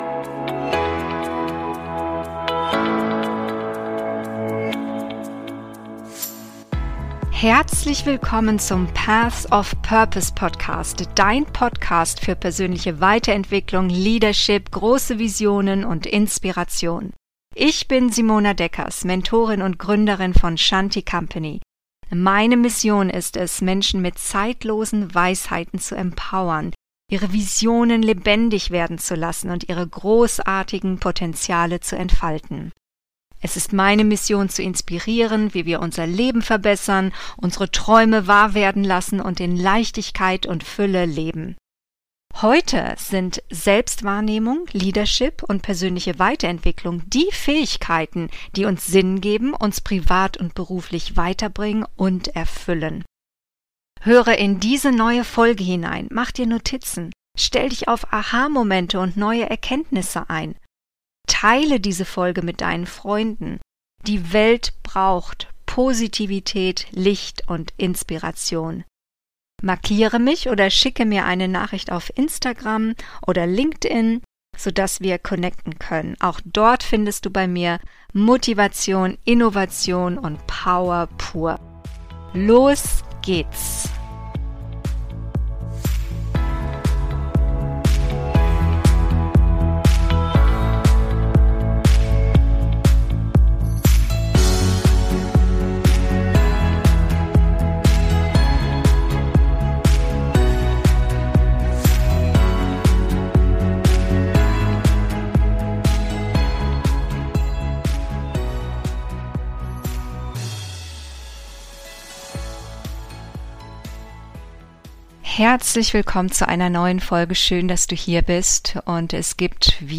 Life is always uphill - Solofolge